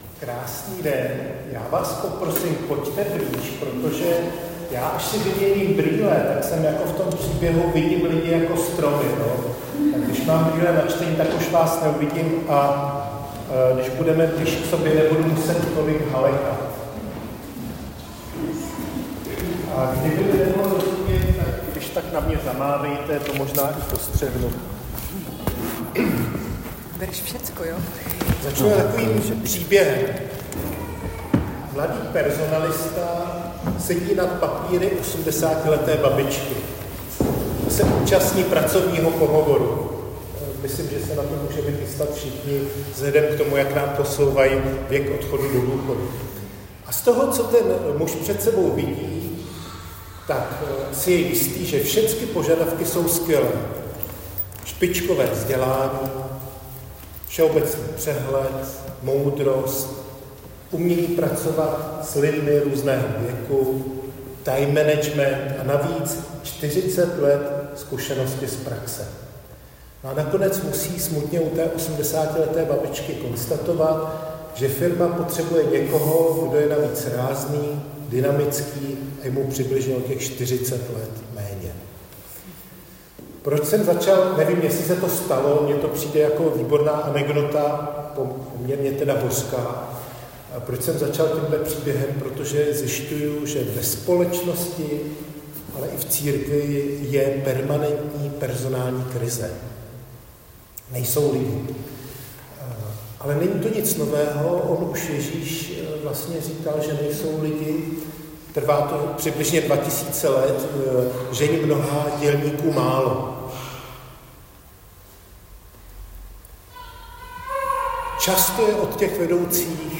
Křesťanské společenství Jičín - Kázání 11.5.2025